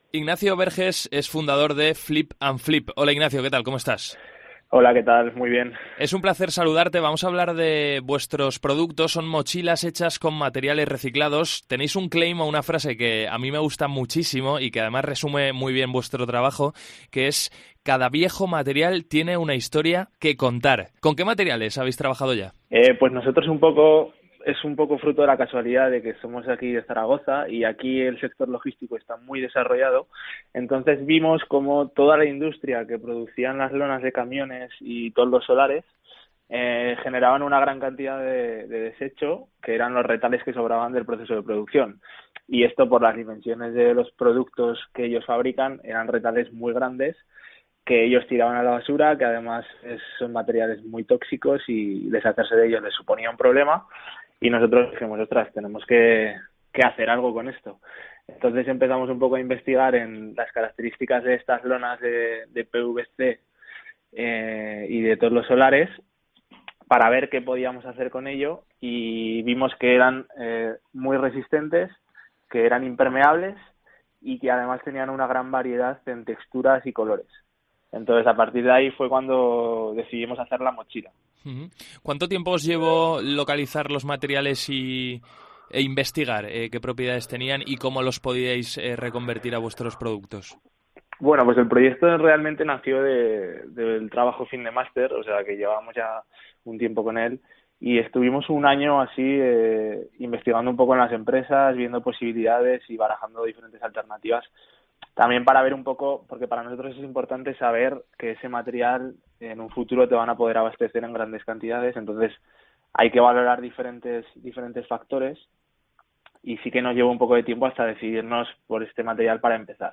El algoritmo verde Entrevista